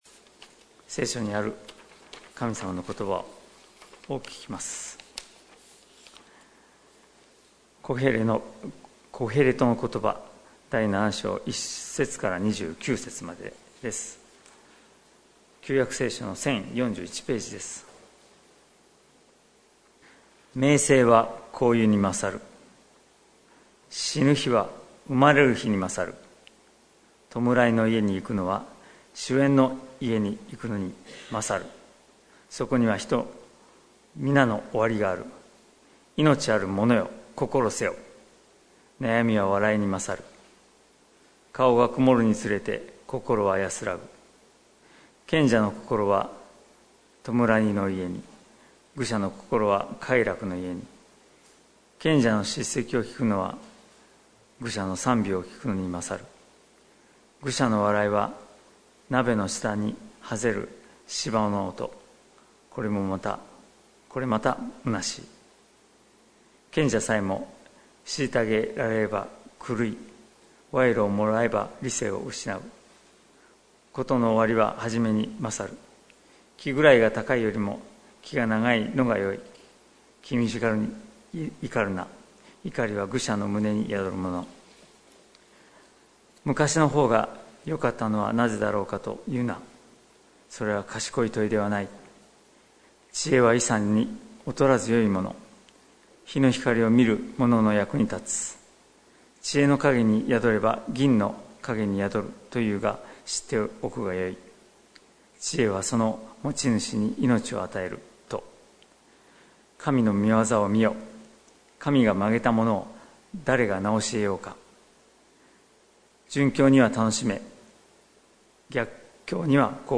2020年10月18日朝の礼拝「命ある者、心せよ！」関キリスト教会
説教アーカイブ。